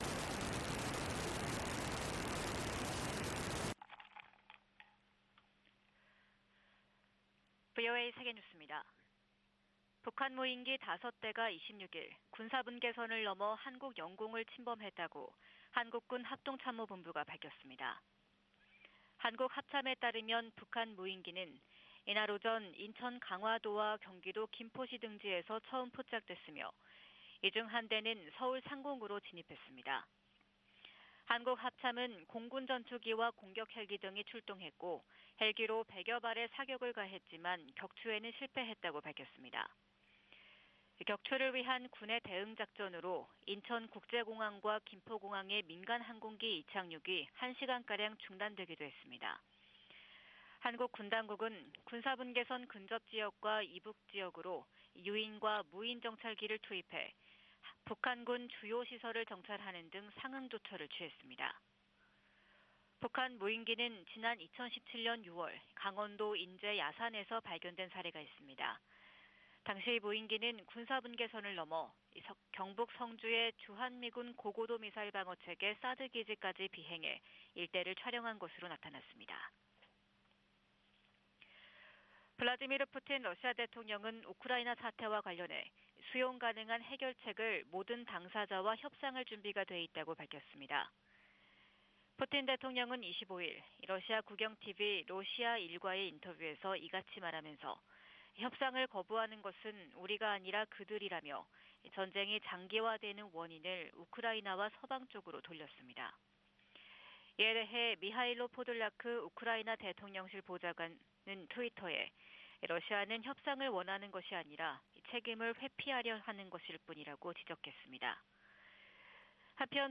VOA 한국어 '출발 뉴스 쇼', 2022년 12월 27일 방송입니다. 북한 무인기가 오늘 5년 만에 남측 영공을 침범해 한국군이 격추 등 대응작전을 벌였지만 격추에는 실패했습니다. 미국 국무부가 북한 정권의 단거리탄도미사일 발사를 규탄하면서 이번 발사가 유엔 안보리 결의에 위배된다고 지적했습니다.